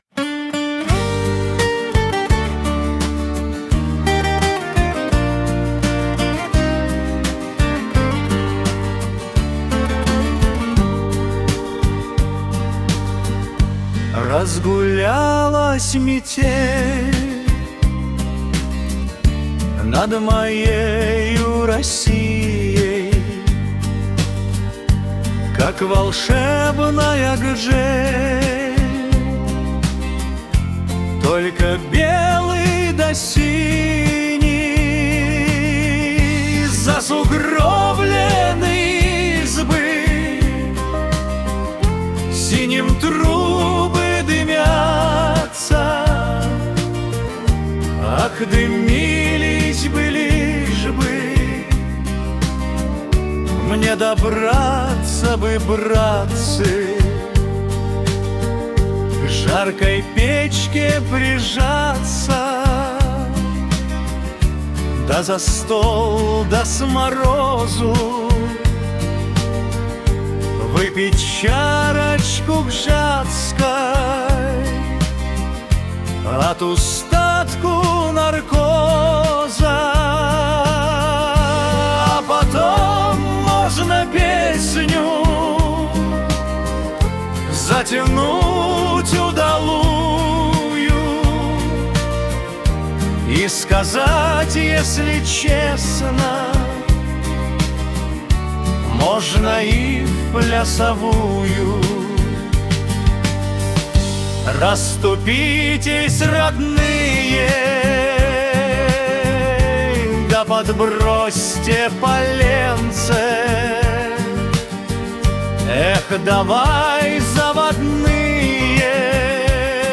• Аранжировка: Ai
• Жанр: Поп